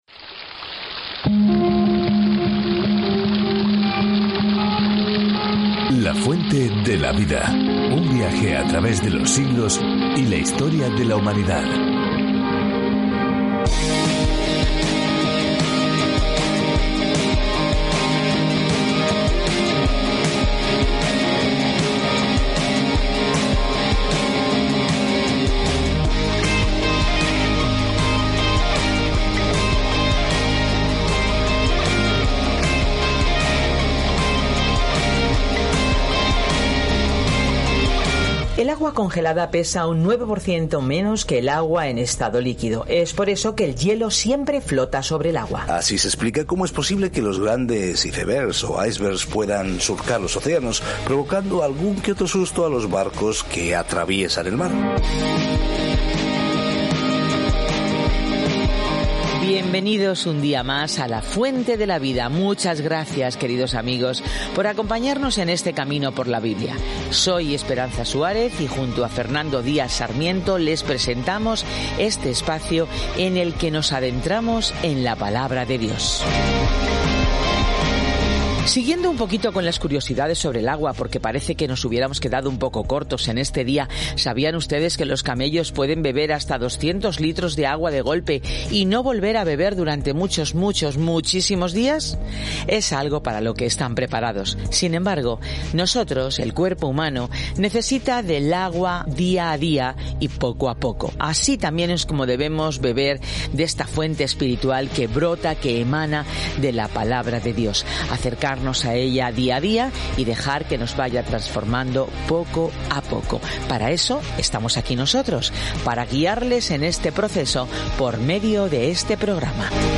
Scripture Judges 8:30-35 Judges 9 Judges 10:1-5 Day 6 Start this Plan Day 8 About this Plan Jueces registra las vidas a veces retorcidas y al revés de las personas que se están adaptando a sus nuevas vidas en Israel. Viaja diariamente a través de Jueces mientras escuchas el estudio de audio y lees versículos seleccionados de la palabra de Dios.